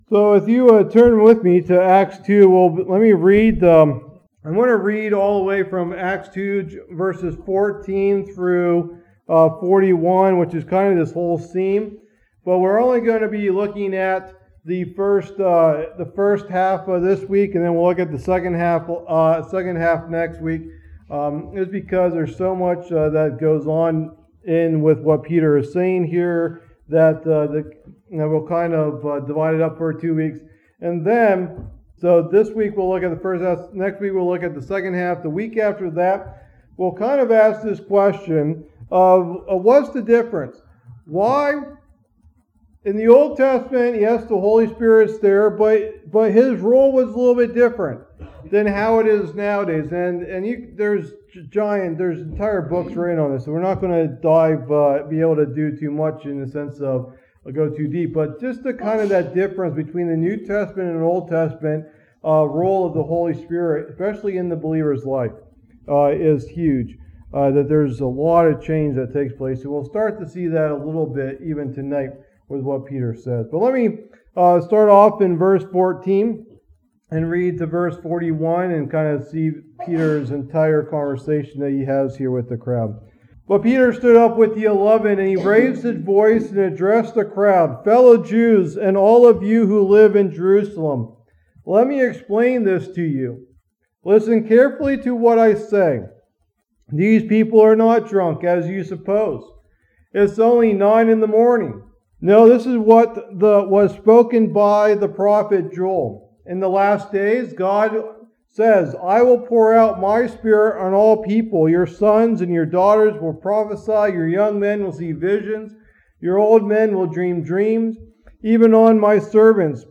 Teaching #5 in the "Book of Acts" study